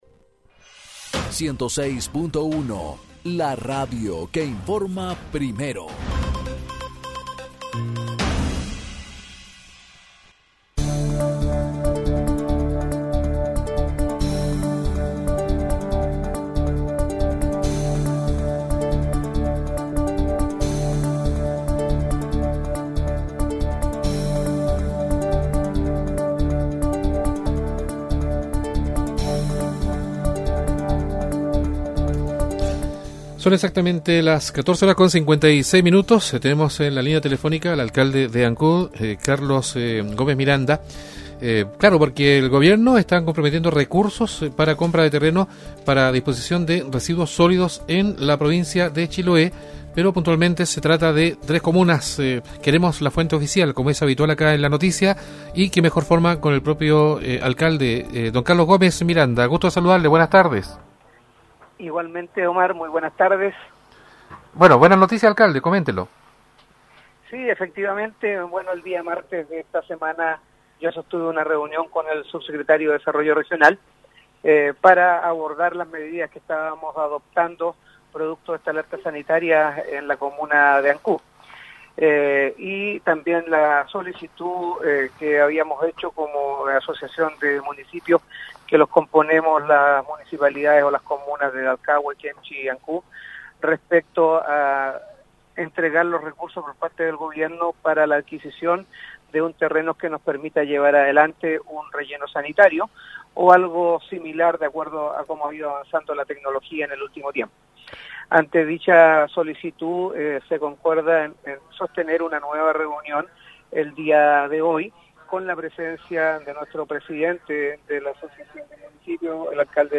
El alcalde de Ancud, Carlos Gómez, conversó con el programa "Viva La Radio" sobre el anunció que realizó la Subsecretaria de Desarrollo Regional y Administrativo, Subdere.